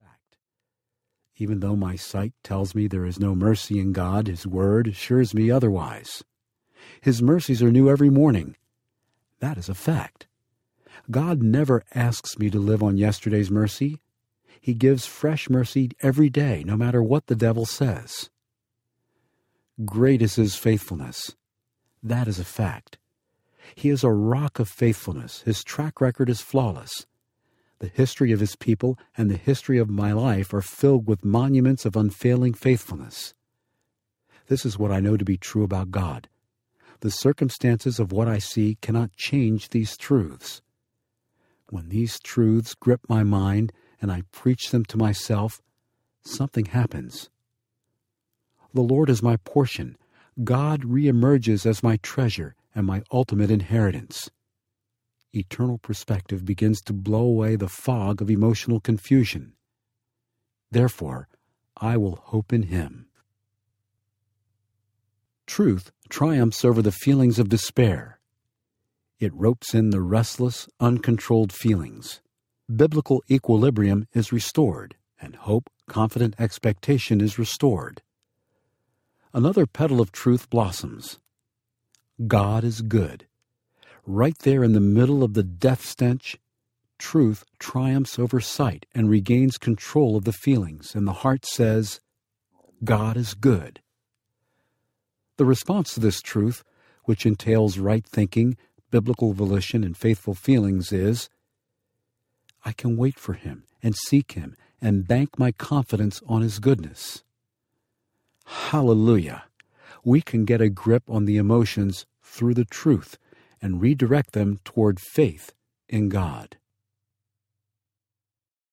Feelings and Faith Audiobook
8.03 Hrs. – Unabridged